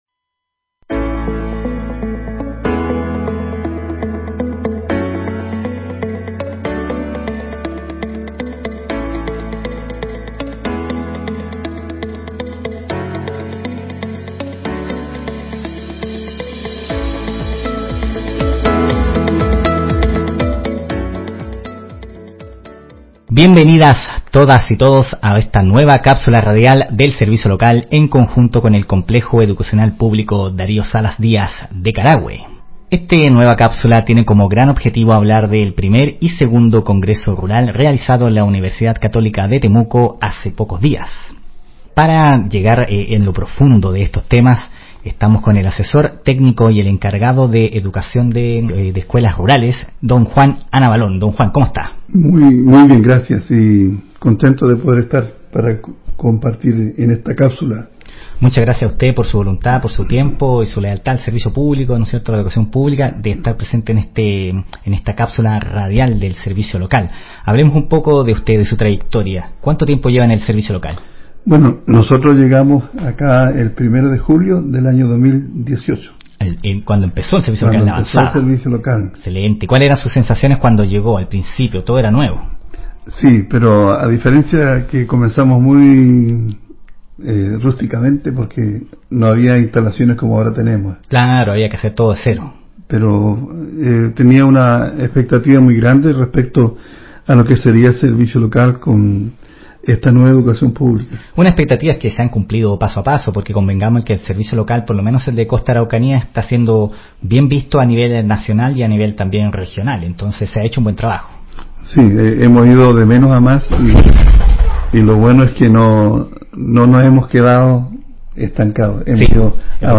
El Servicio Local de Educación Pública Costa Araucanía (SLEPCA) y el Complejo Educacional Darío Salas Díaz de Carahue, se unieron para realizar Cápsulas Radiales en el Locutorio del establecimiento educacional.